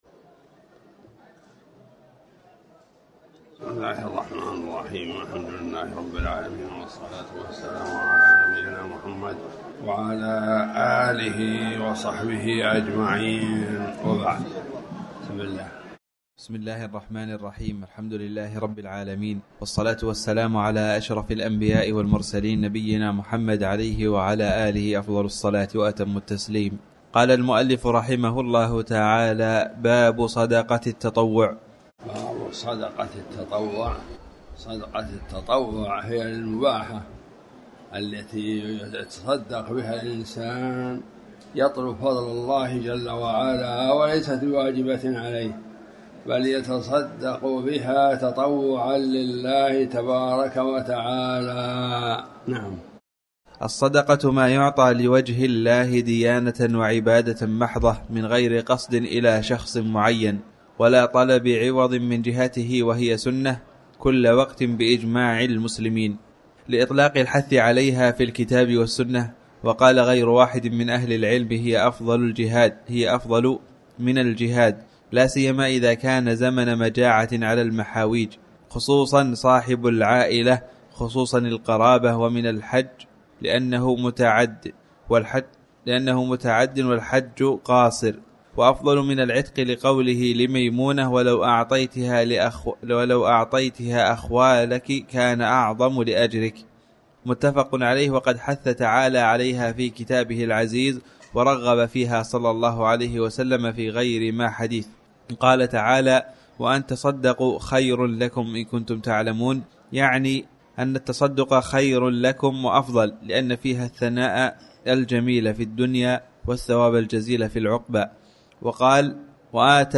تاريخ النشر ٨ ربيع الأول ١٤٤٠ هـ المكان: المسجد الحرام الشيخ